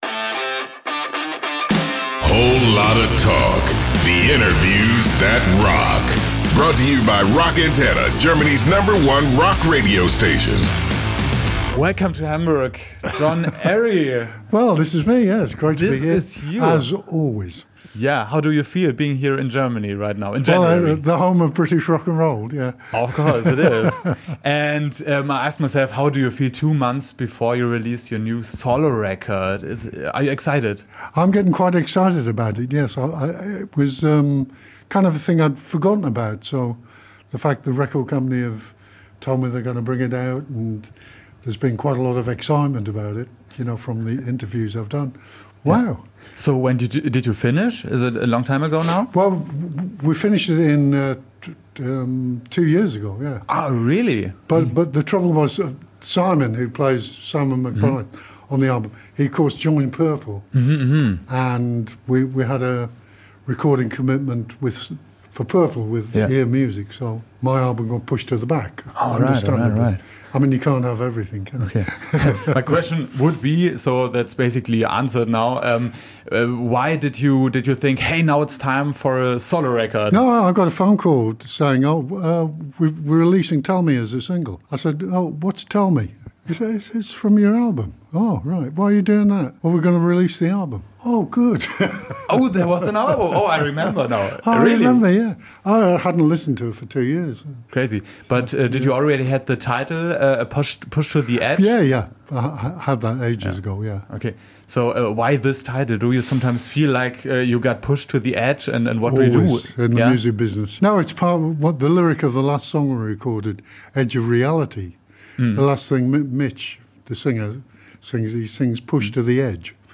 After countless concerts and number one albums with the band, his latest solo work, "Pushed to the Edge," is now being released. Find out how this came about and why Don Airey almost forgot about his own album shortly before its release in this exclusive interview with ROCK ANTENNE, recorded this January!